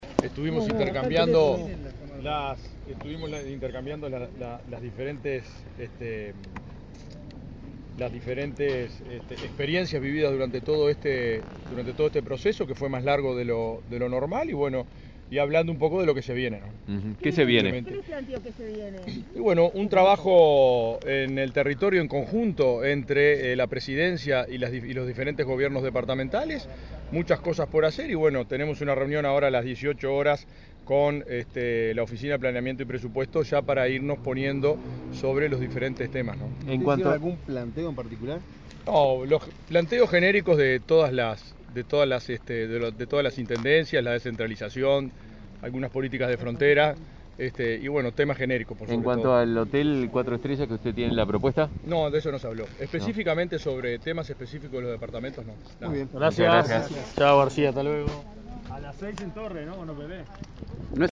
Escuche las declaraciones del intendente electo de Lavalleja, Mario García.